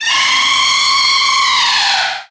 legend-of-zelda-the-wind-waker-zombie-scream_26115.mp3